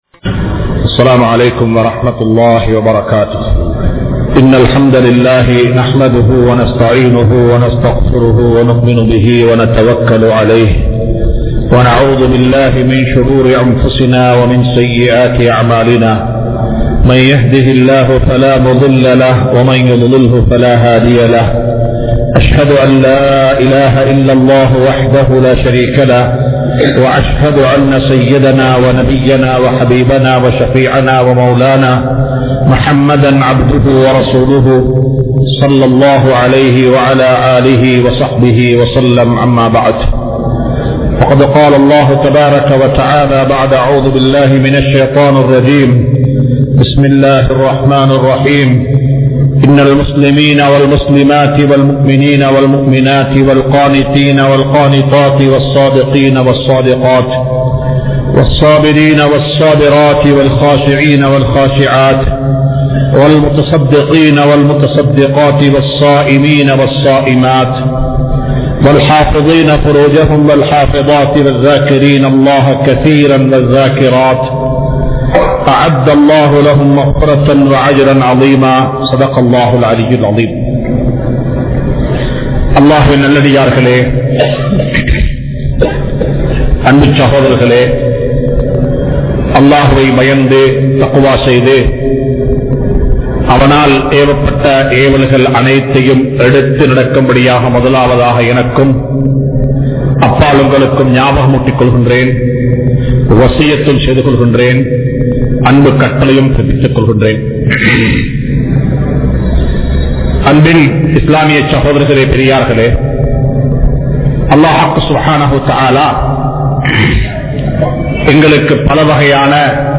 Allah`vai Athihamaaha Ninaivu Koorungal (அல்லாஹ்வை அதிகமாக நினைவு கூறுங்கள்) | Audio Bayans | All Ceylon Muslim Youth Community | Addalaichenai
Colombo 04, Majma Ul Khairah Jumua Masjith (Nimal Road)